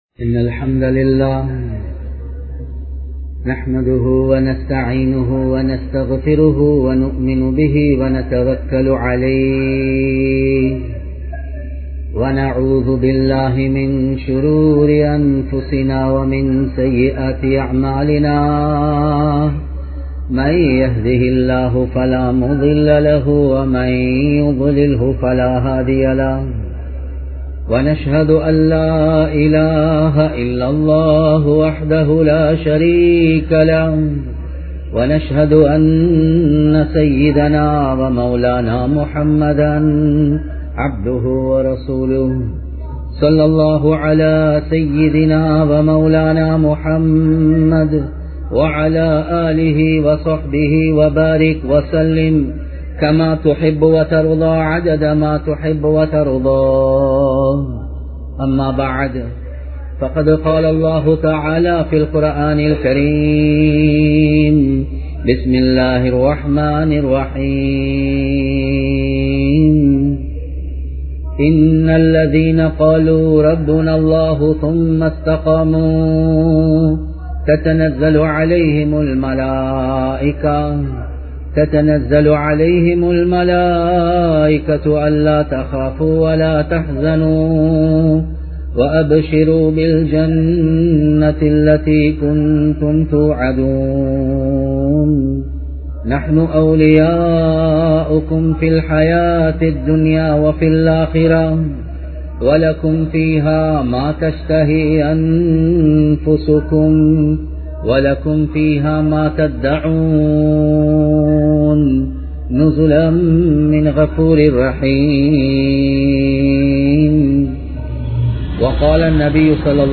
ஏனைய மதத்தவர்களுடன் நபியவர்களின் முன்மாதிரிகள் | Audio Bayans | All Ceylon Muslim Youth Community | Addalaichenai
Colombo 09, Dematagoda, School Lane Masjid